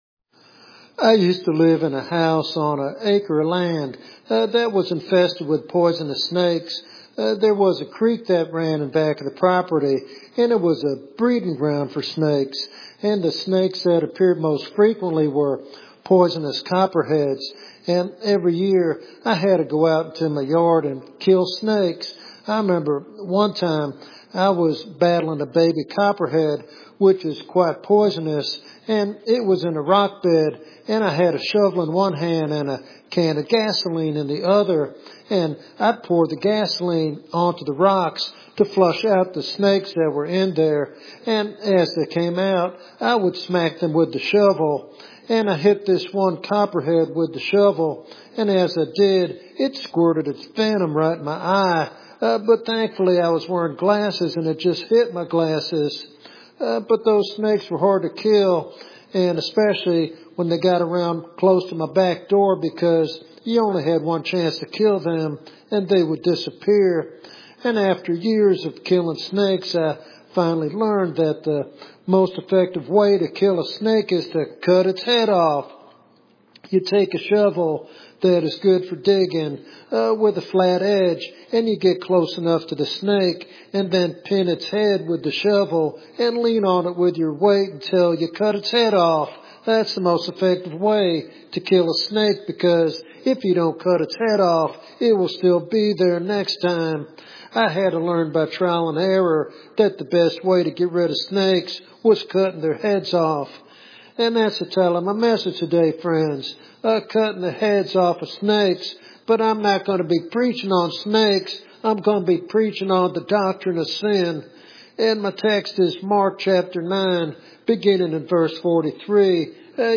In this powerful topical sermon